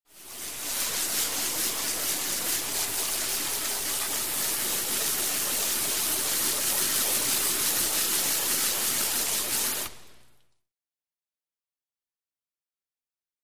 Hose, Spray
Spraying Metal Car Door In A Large Garage